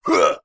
attack_default.wav